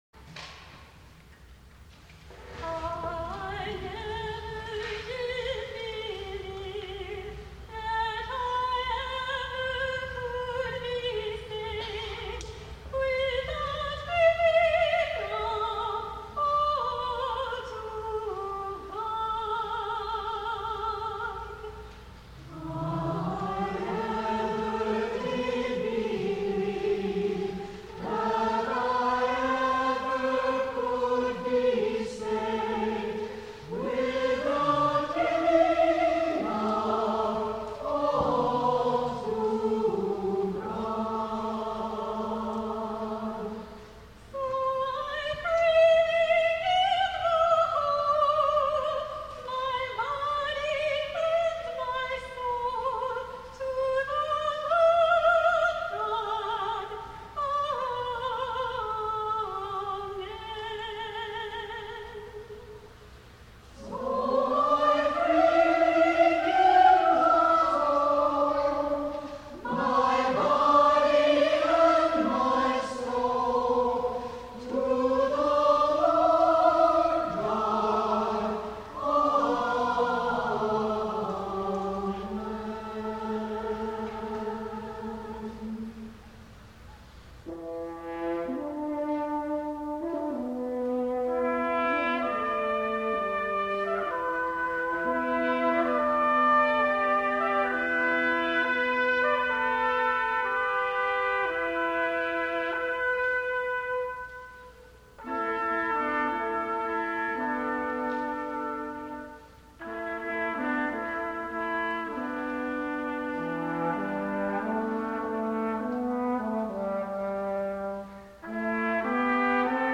for Brass Quintet (1994)
with singing